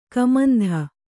♪ kamandha